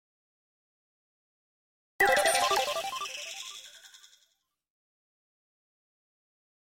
Звуки для игр
Звук подбора бонуса или экстра жизни в аркаде